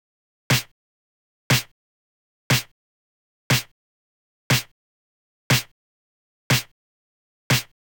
29 Snare.wav